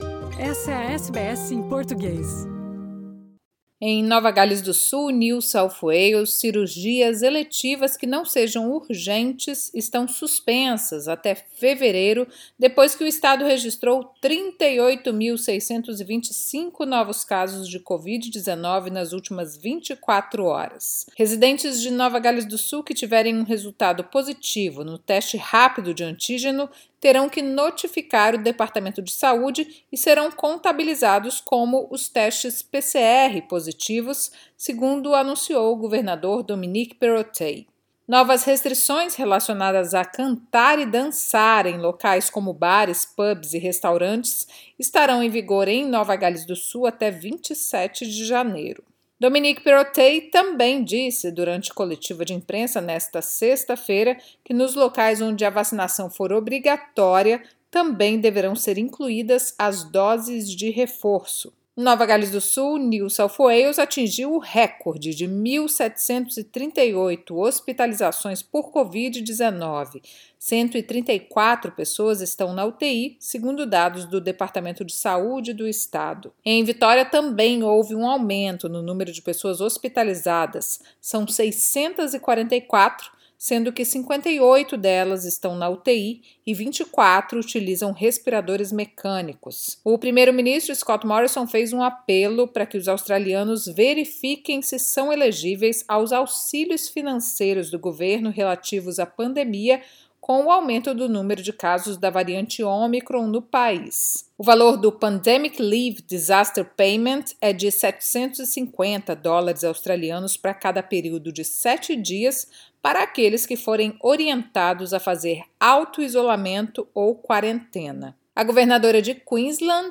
Confira o resumo das notícias desta sexta-feira, 7 de janeiro, relacionadas à pandemia na Austrália.